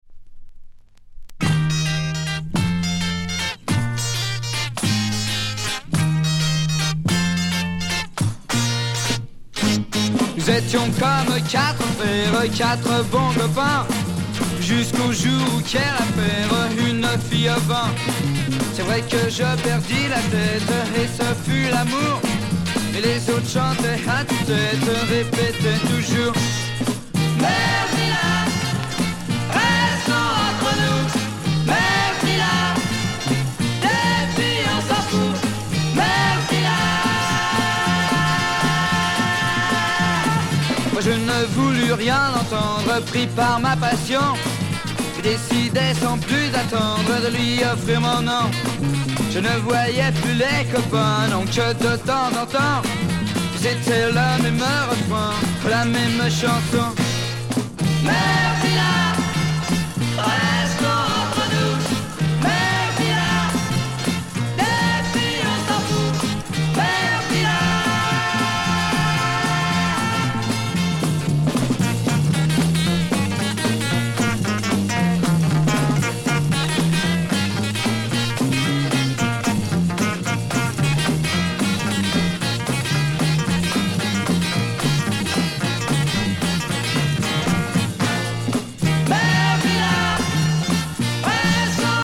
Teen French beat punk 66